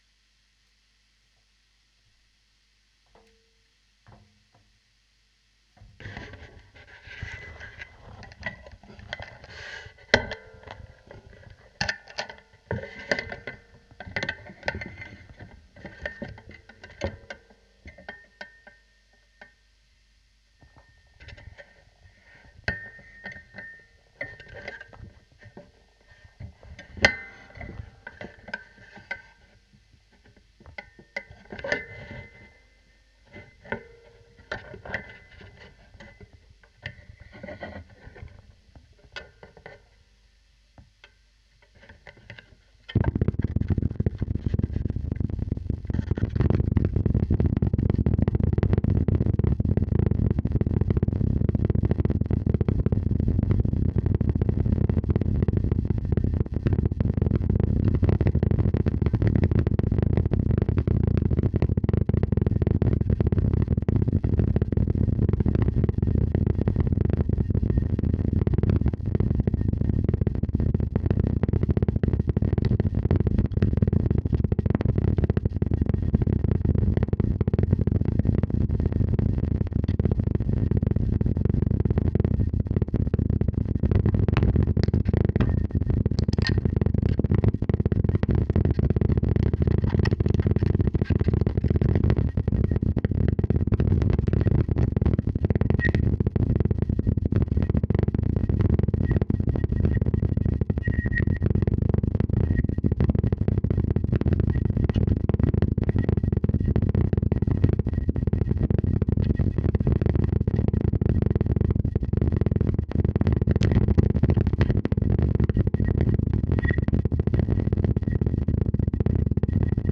2016 Kontaktmikro Feedback (Video)
(aufgenommen mit Rode NT-4 Stereo Mikrophon und Tascam HD-P2 Digital Rekorder)
03 kontaktmikro Feedback (Video)-Take327-cut.flac